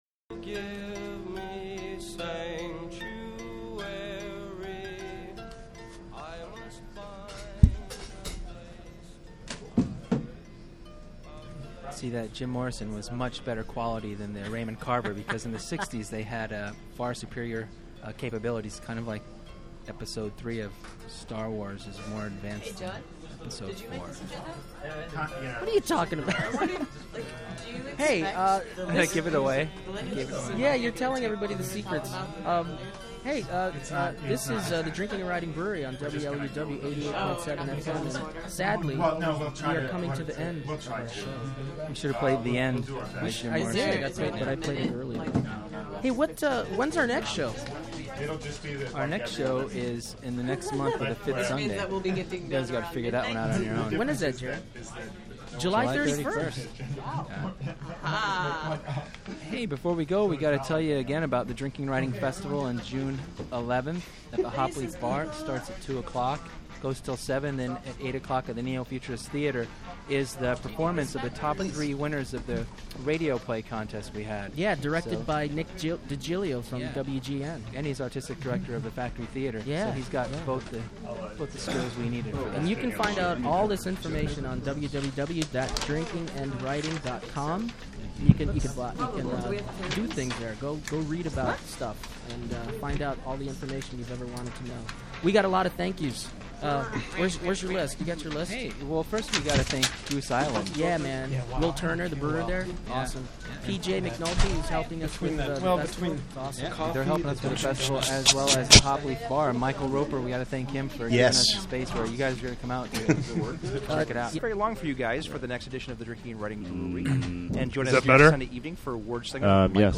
: Season One : June 6, 2004 - May 29, 2005 : : wluw 88.7 fm chicago : May 29: production meeting mp3 : stream (full program) Members of blind spot have a live production meeting on the air where they discuss next season.
production_meeting.mp3